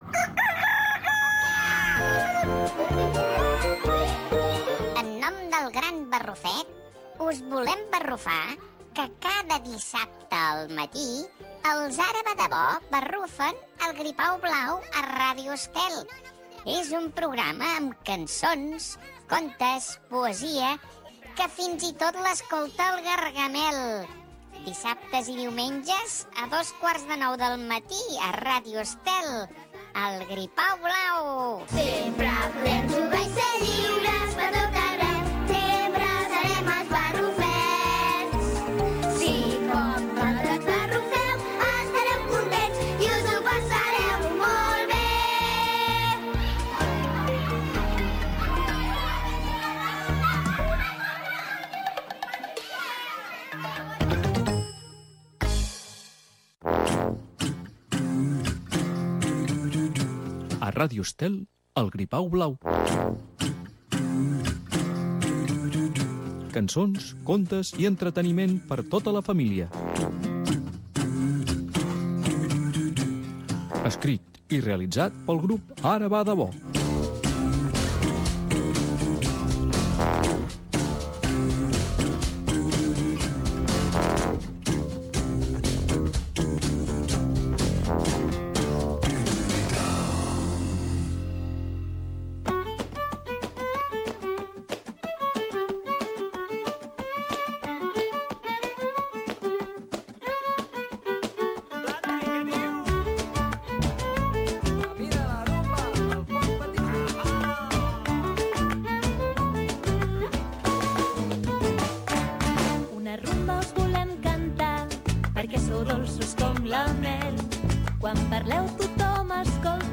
El Gripau blau Programa infantil amb personatges i convidats que proposaran exercicis de gastronomia per a nens, poesia, música i contes.